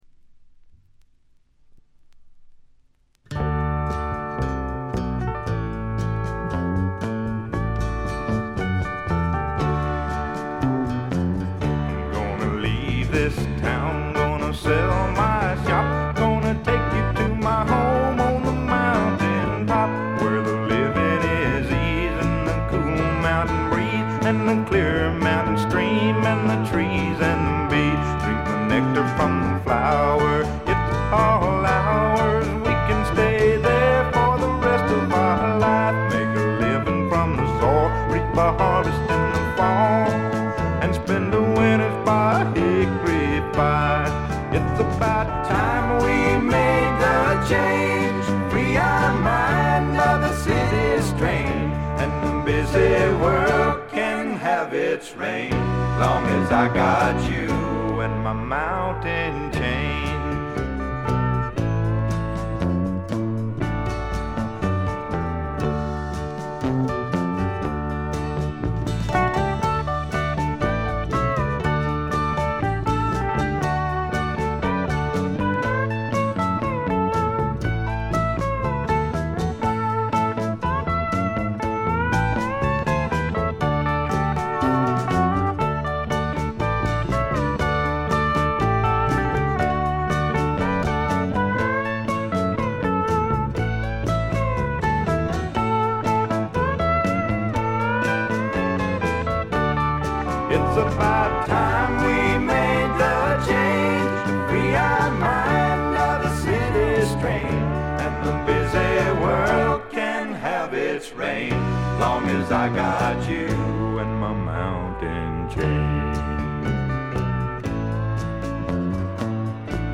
軽いチリプチが少々。
試聴曲は現品からの取り込み音源です。